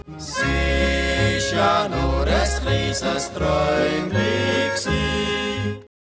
Satz: Dem Legato-Thema, Takt 27-30, entspricht ziemlich genau die Melodie des volkstümlichen Schlagers "S'isch ja nur es chliises Träumli gsii..." von 1960 mit den "Boss-Buebe".
vlsonate-2.wav